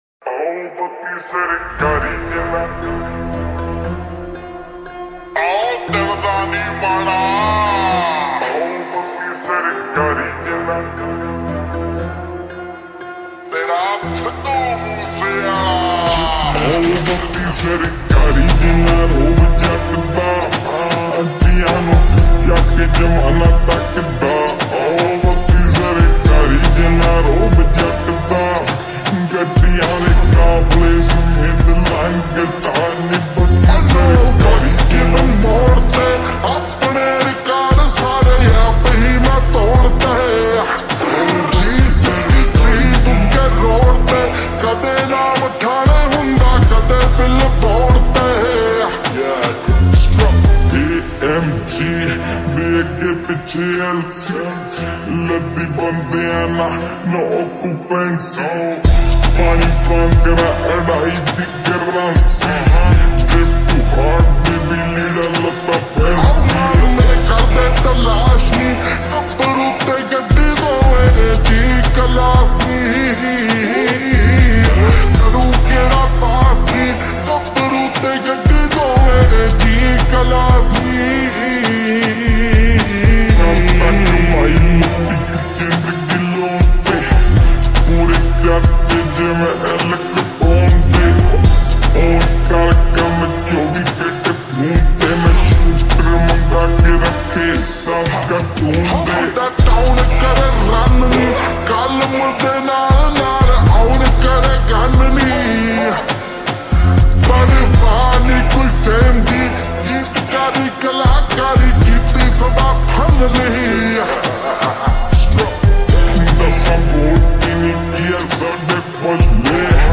MESHUP SONG 🥵🎧 (SOLVED REVERB